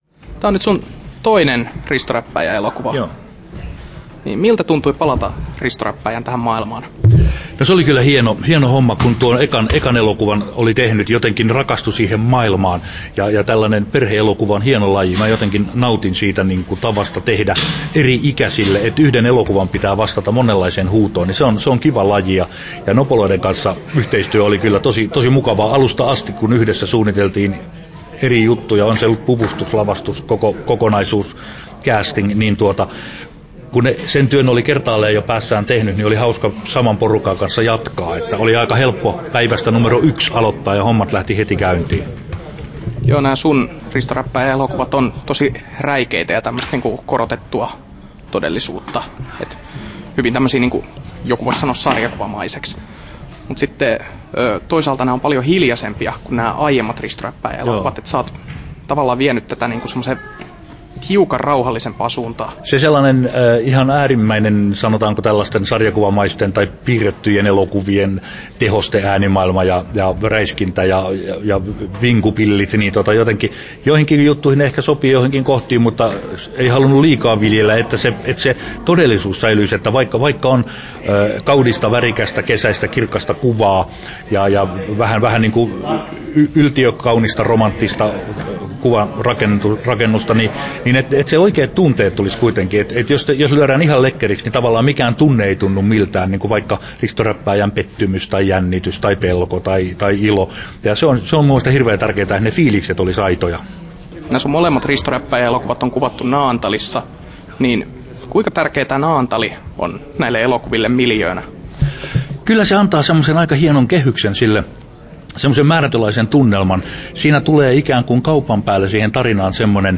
Timo Koivusalo ja Risto Räppääjä ja Sevillan saituri • Haastattelut
Haastattelussa Timo Koivusalo Kesto